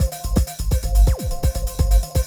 BEEPER    -L.wav